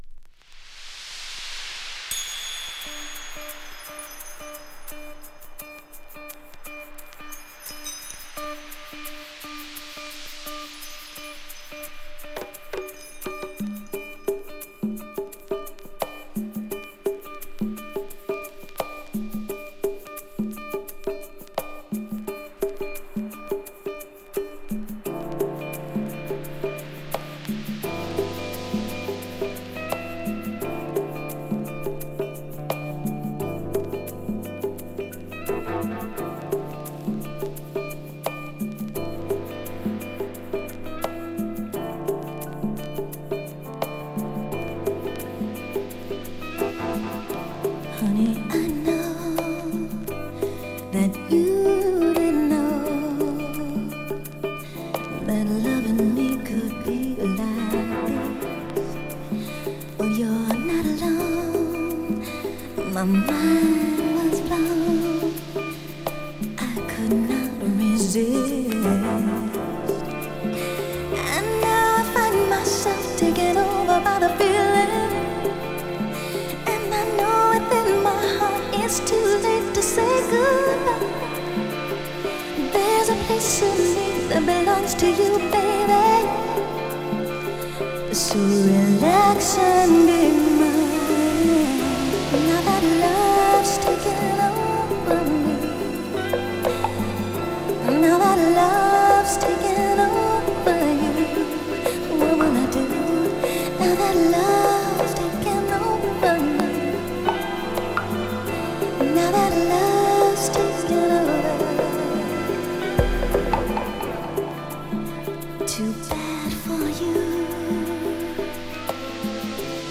ウィスパリングヴォイスとUK R&B色濃いサウンドが見事にマッチ！